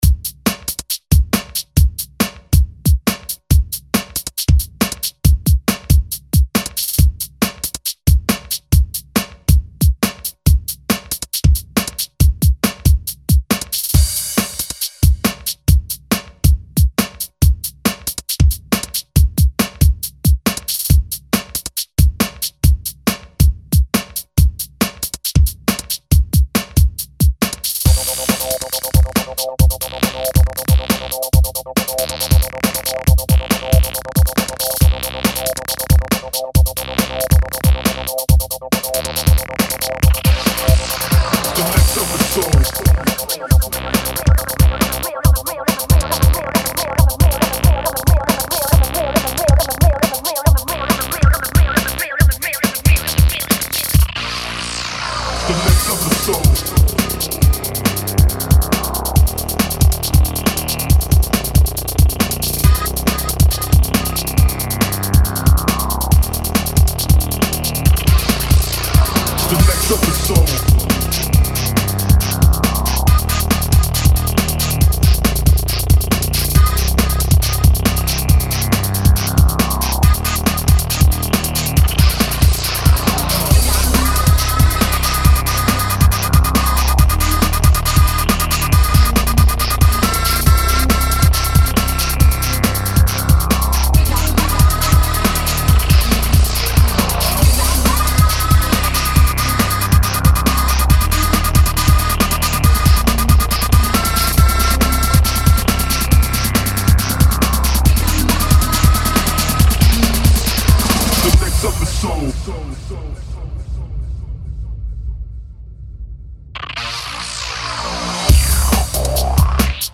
Category Archives: Breakbeat
a funky breaks track
A badass breaks track